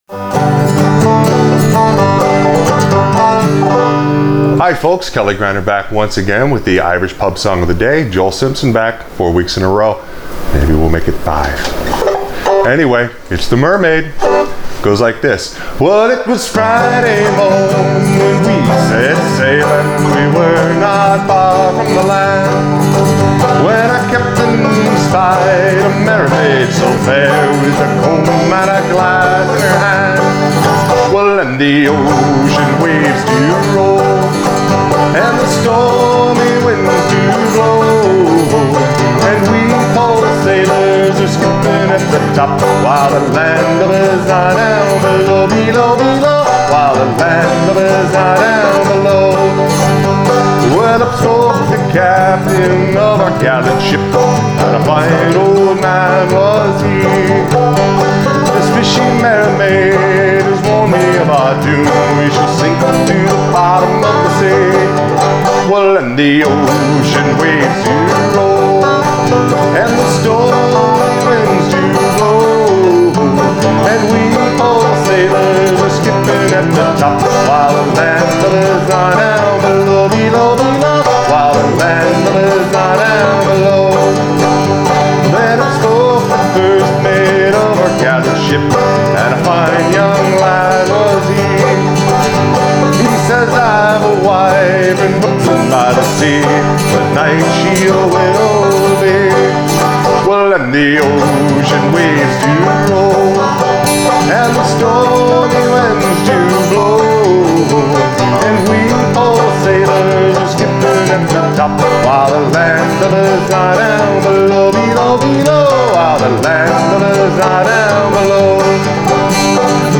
Irish Pub Song Of The Day – The Mermaid Accompaniment for Frailing Banjo